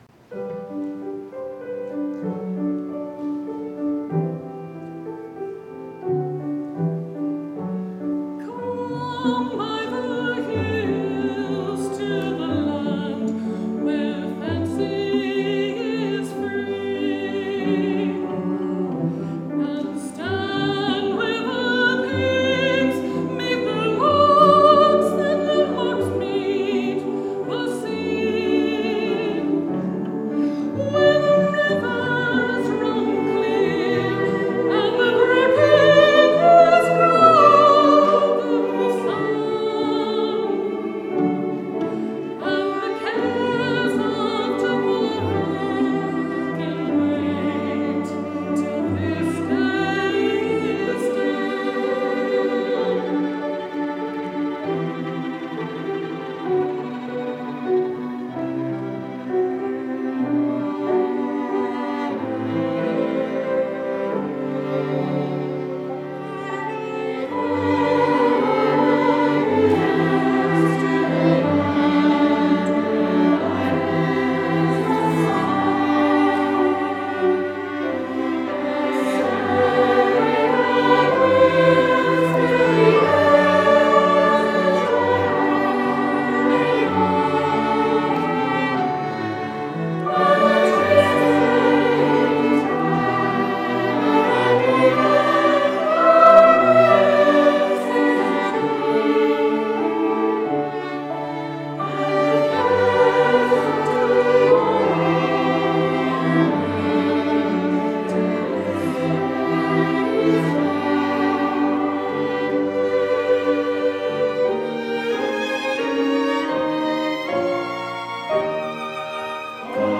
Live Recordings from Our Concerts Here is a small collection of our music from previous concerts.
Note: These recordings were captured live using personal recording devices during our concerts, so you may notice background sounds.